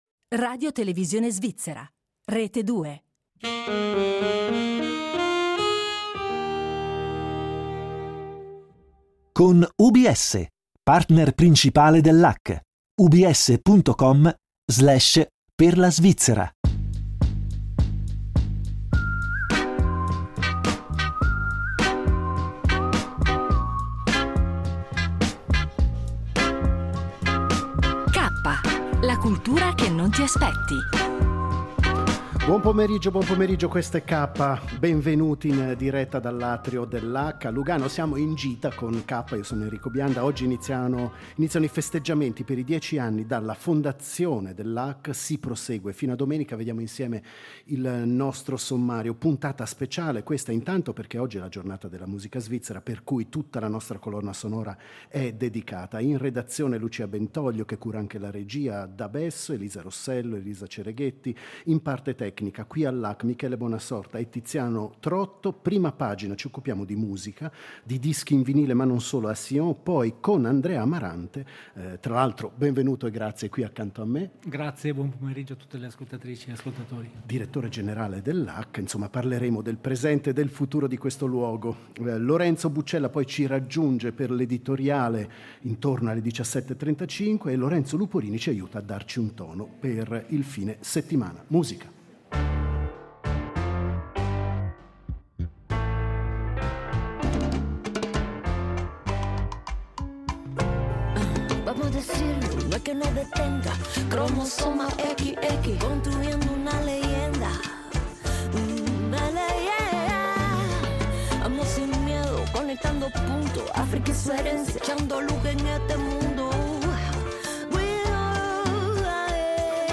La puntata speciale di Kappa , in diretta dall’atrio del LAC di Lugano, celebra i 10 anni dalla fondazione del centro culturale.